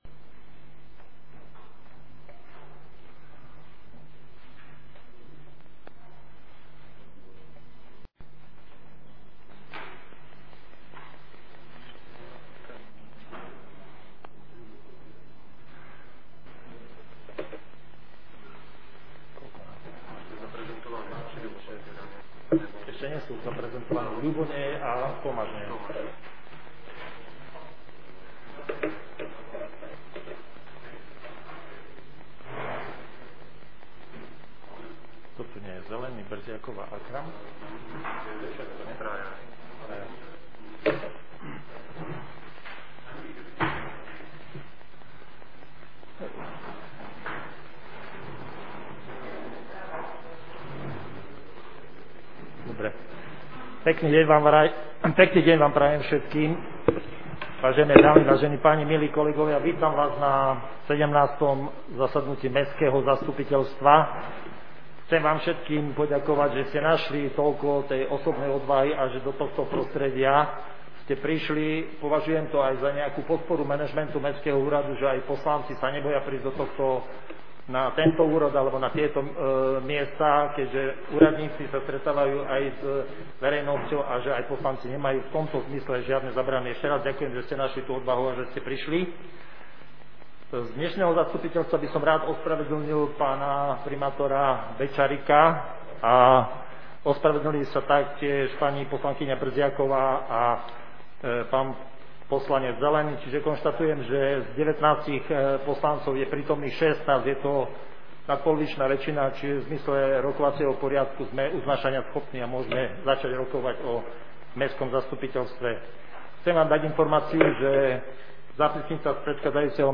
Zvukový záznam zo zasadnutia mestského zastupiteľstva